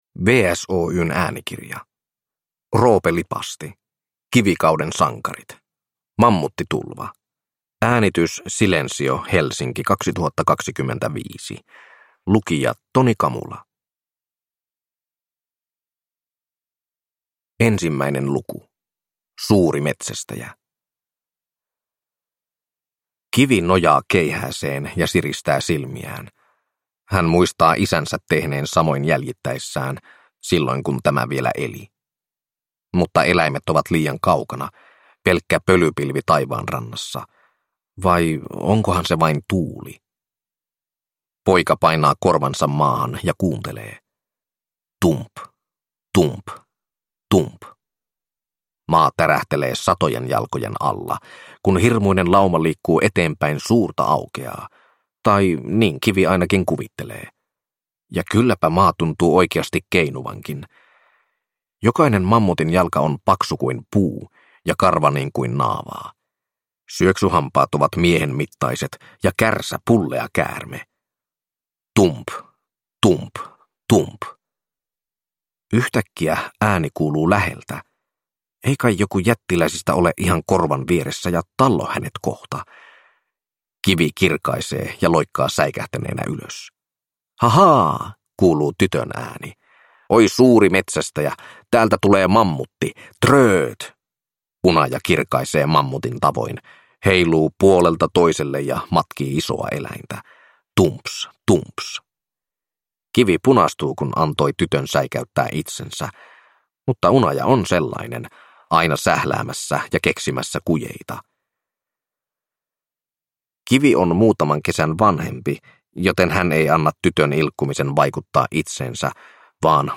Kivikauden sankarit - Mammuttitulva – Ljudbok